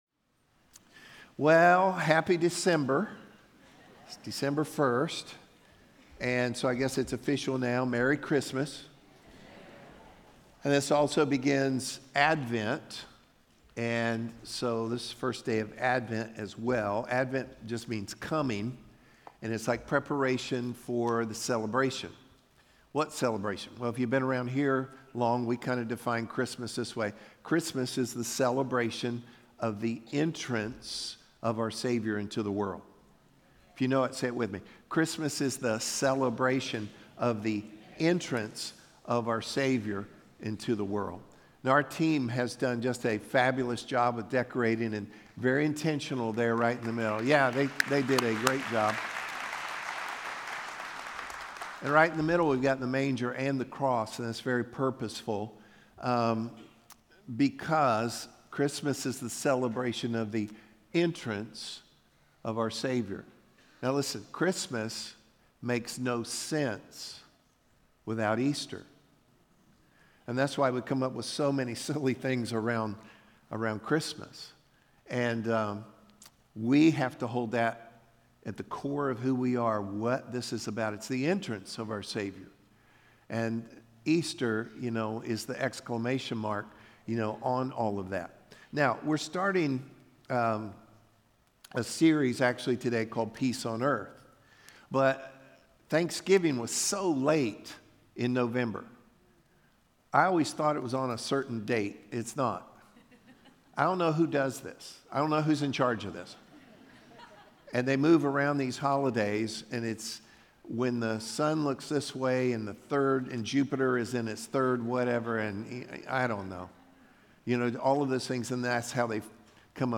Meadowbrook Chuch exists to help people move from where they are to where God wants them to be. Listen weekly to relevant Biblical teaching